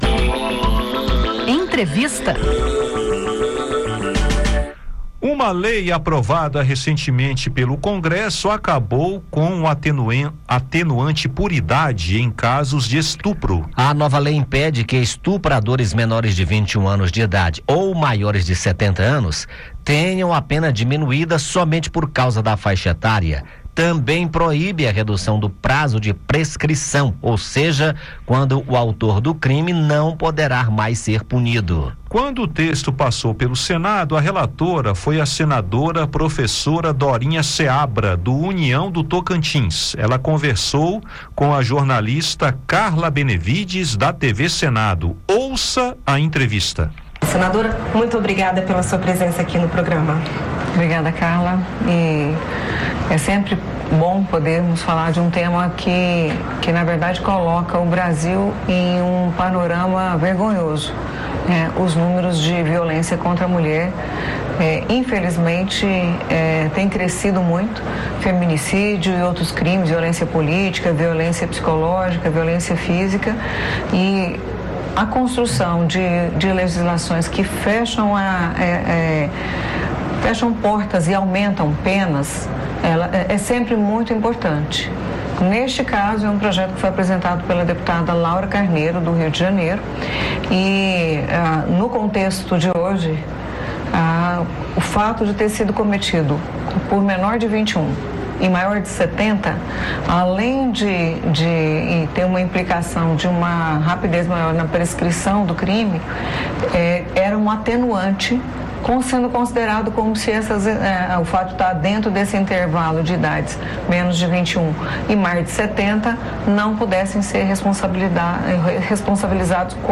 Relatora do projeto no Senado, a senadora Professora Dorinha Seabra (União-TO) comenta as mudanças e reforça a importância da nova norma para o combate à impunidade e à violência sexual.